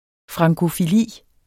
Udtale [ fʁɑŋgofiˈliˀ ]